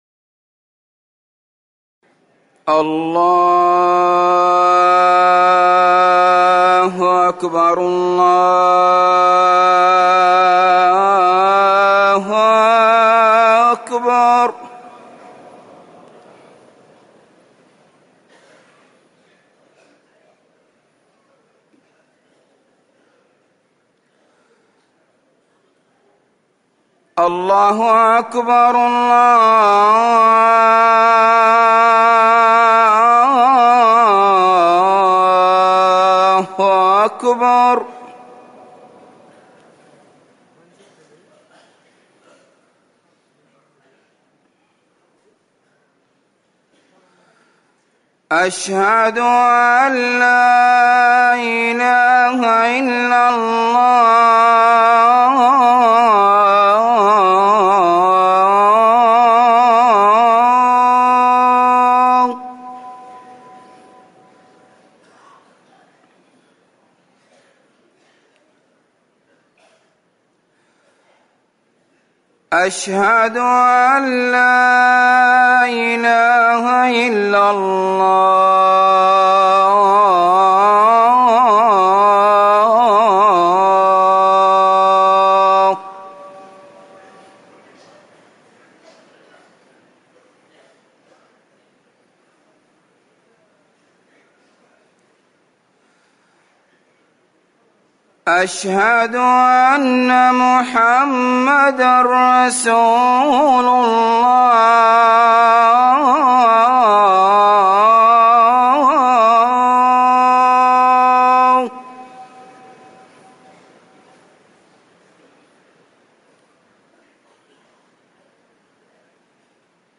أذان الفجر الثاني
تاريخ النشر ٢ محرم ١٤٤١ هـ المكان: المسجد النبوي الشيخ